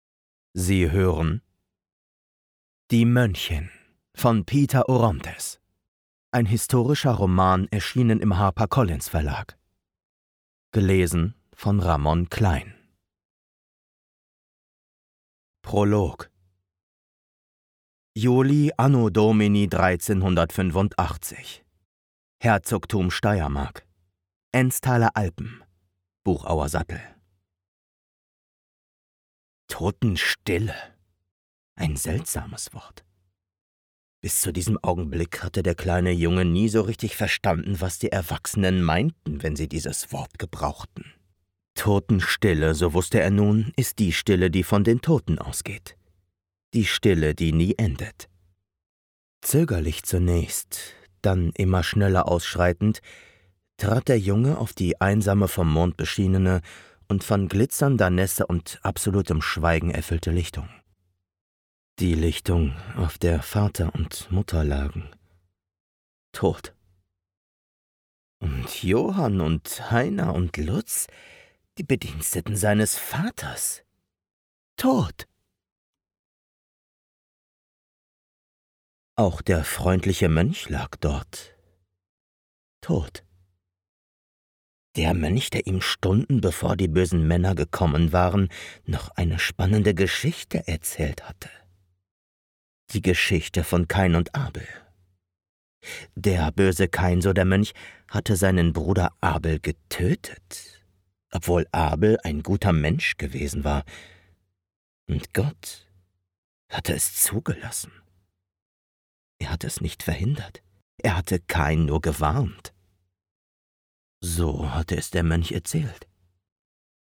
Audiobook Hostorical Novel
Young Adult
Acoustics: Vocal booth including Caruso Iso Bond 10cm, Basotect for acoustic quality.